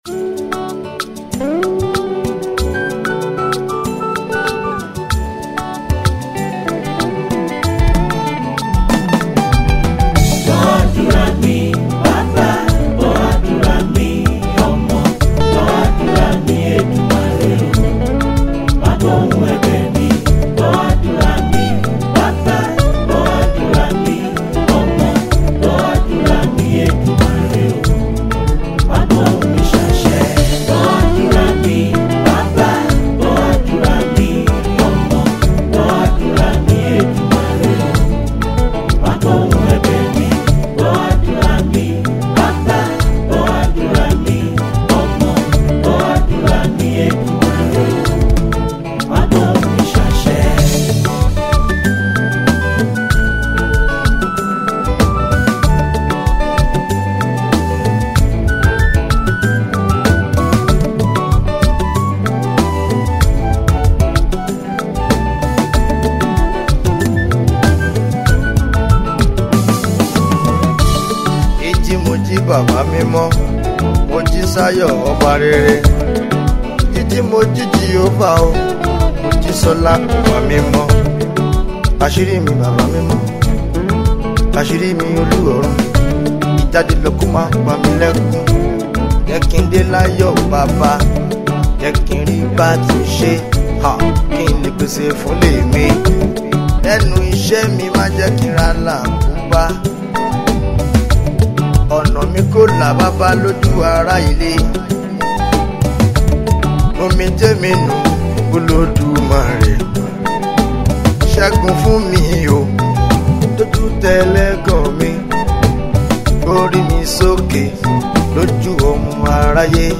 gospel juju
prayer song